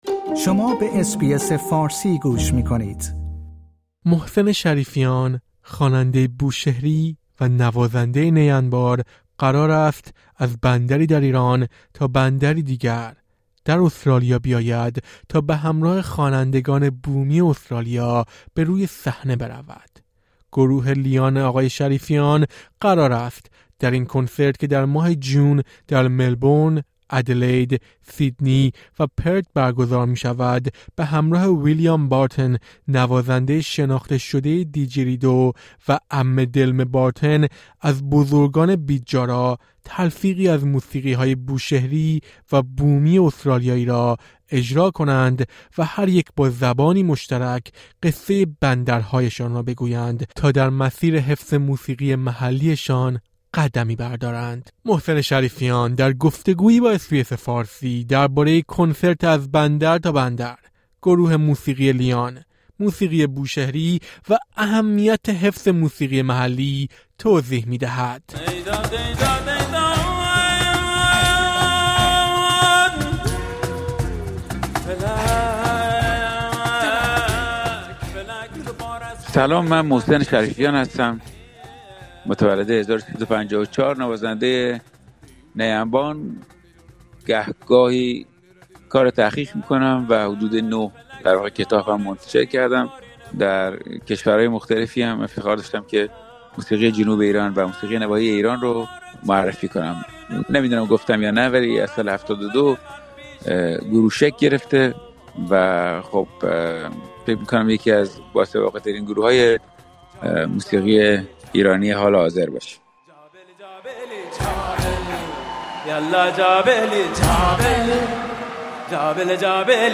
محسن شریفیان در گفت‌وگویی با اس‌بی‌اس فارسی درباره کنسرت «از بندر تا بندر»، گروه موسیقی لیان، موسیقی بوشهری و اهمیت حفظ موسیقی محلی توضیح می‌دهد.